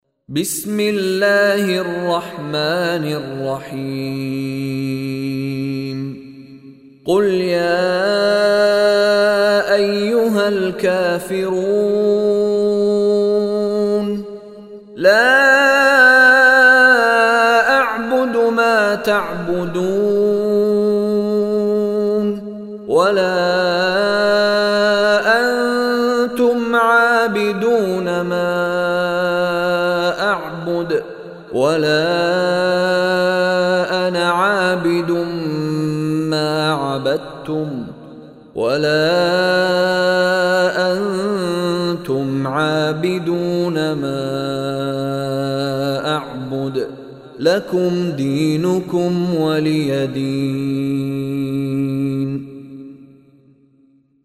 Surah Kafirun Recitation by Sheikh Mishary Rashid
Listen online arabic recitation of Surah Kafirun, recited by Sheikh Mishary Rashid Alafasy.